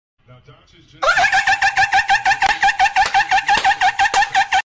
This Dolphin Laugh ICANT🔥 Credits: sound effects free download